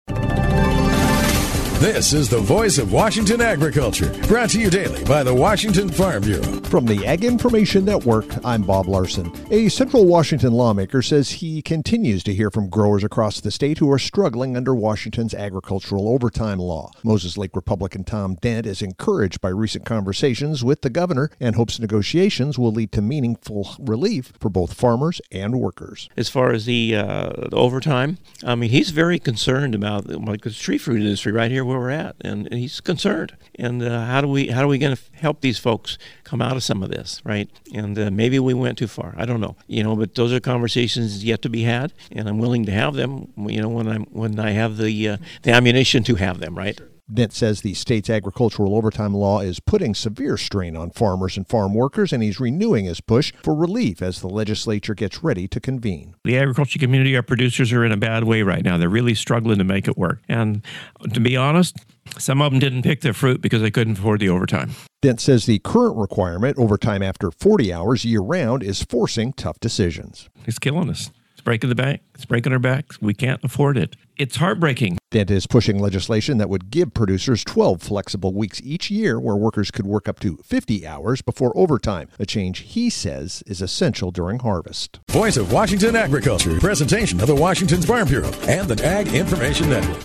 Monday Apr 6th, 2026 31 Views Washington State Farm Bureau Report